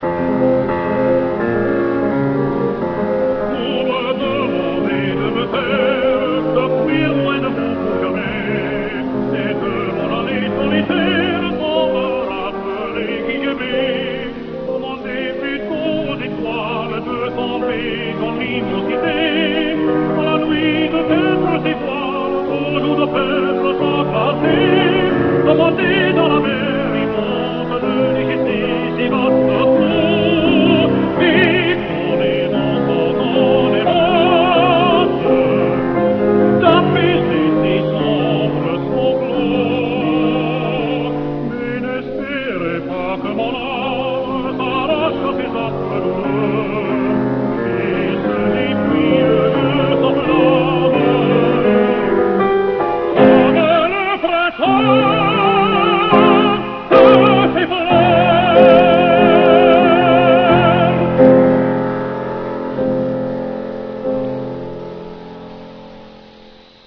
T   E   N   O   R
Full response mono - 5.5 kHz - 1 minute 7 seconds